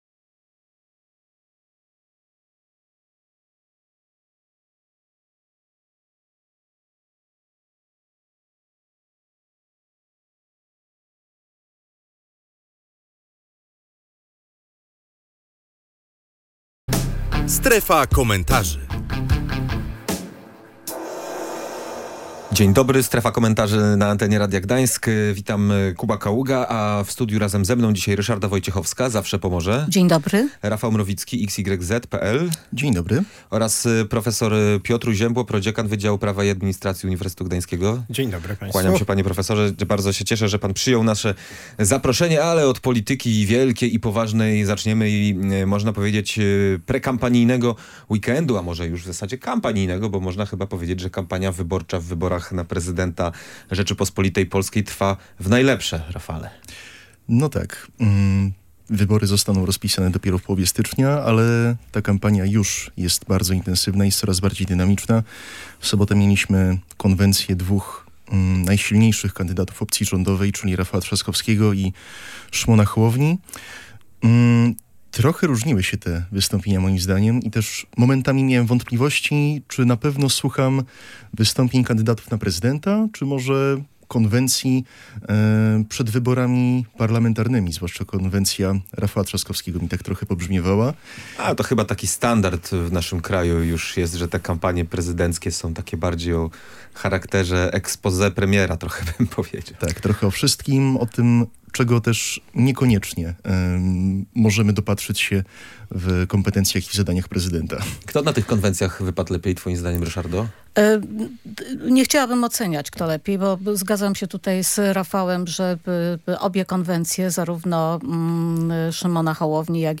W dzisiejszej dyskusji goście poruszali temat prekampanii prezydenckiej oraz ostatnich wieców kandydatów. Zastanawiali się też nad zasadnością prawną istnienia prekampanii, zwracając uwagę na to, że w Polsce nie ma jednoznacznych regulacji, dotyczących tego zjawiska. W trakcie rozmowy koncentrowali się również na roli prezydenta, który – jak zauważyli – ma ograniczone kompetencje w wielu kwestiach.